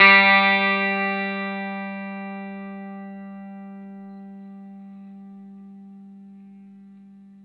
R12NOTE G+.A.wav